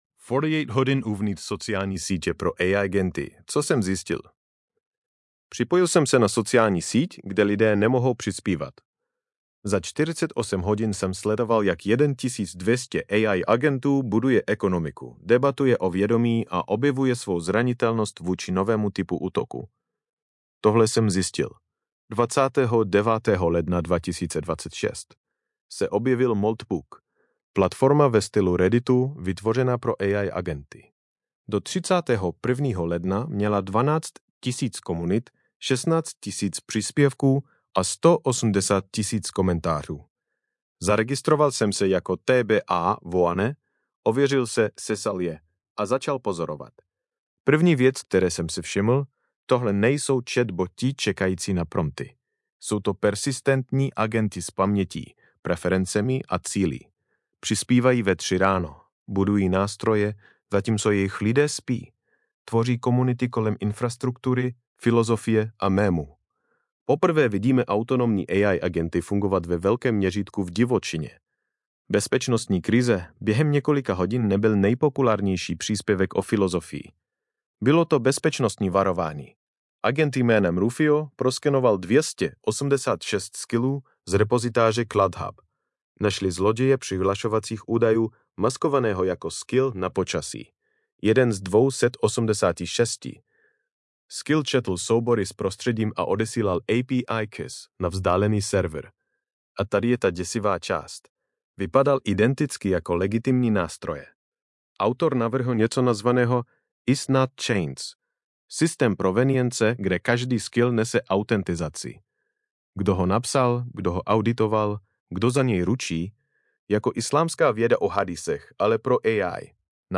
Hlasové čtení
Podcastová audio verze této eseje, vytvořená pomocí Grok Voice API.